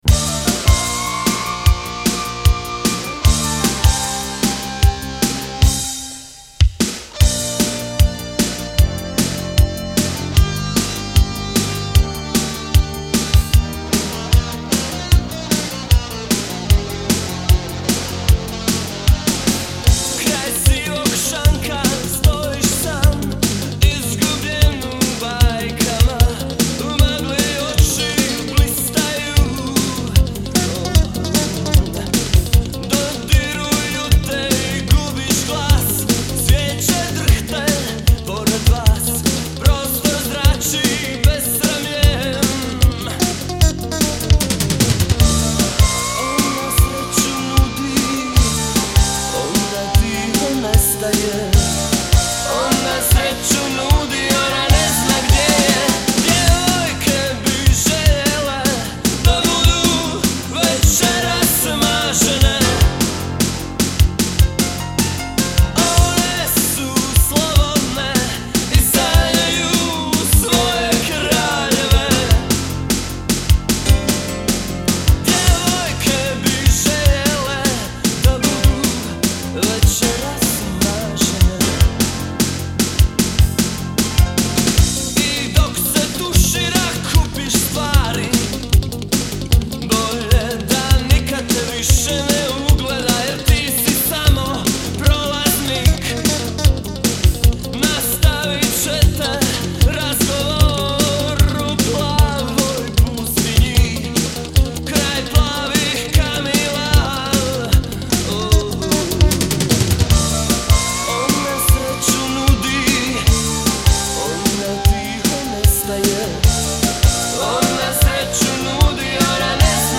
Pop - Rock